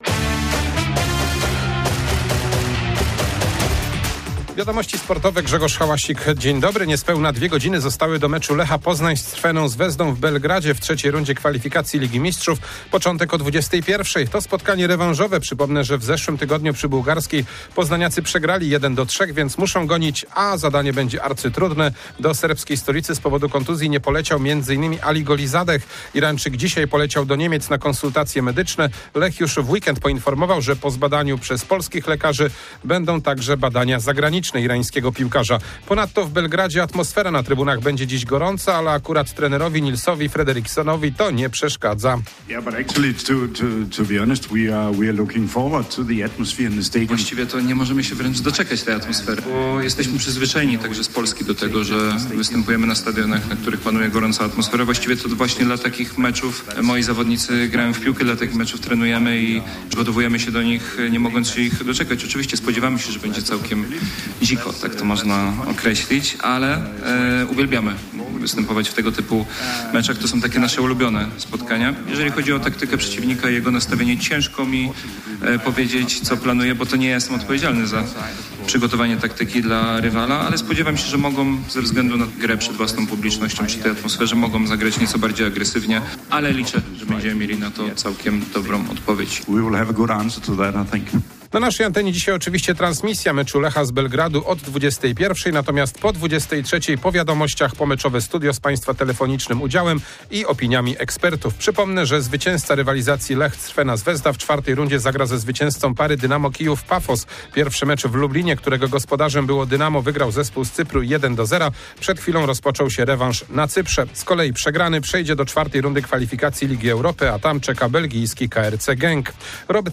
12.08.2025 SERWIS SPORTOWY GODZ. 19:05